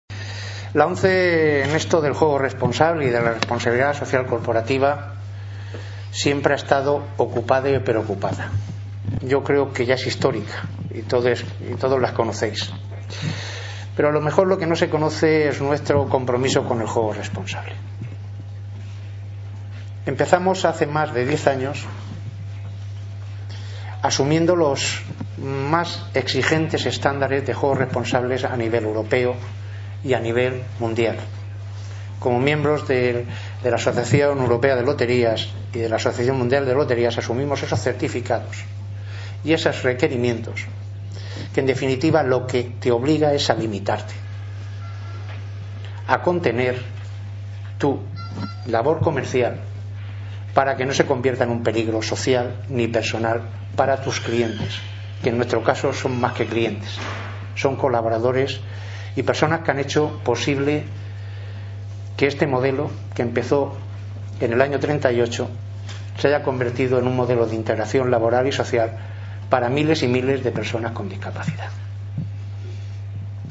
FEJAR promueve una nueva jornada científica sobre la problemática social del Juego